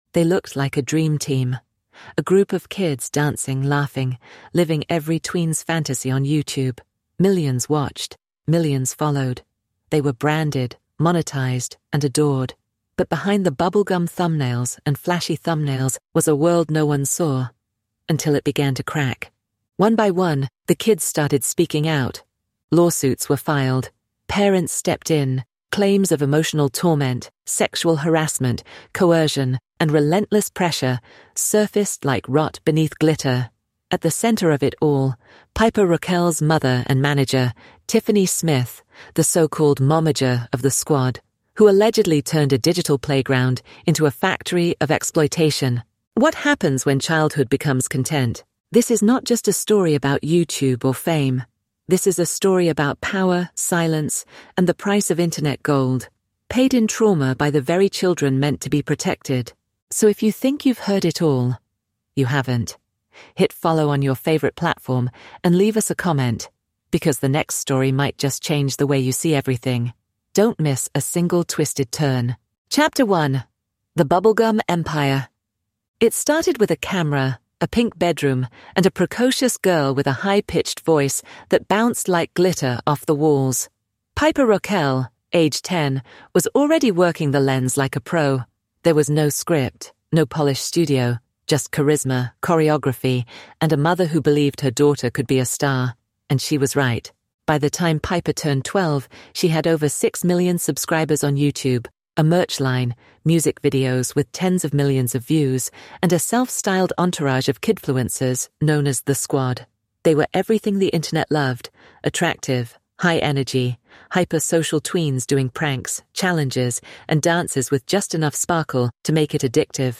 This powerful, true-crime Netflix-style documentary exposes the hidden abuse behind one of YouTube’s most popular child influencer empires.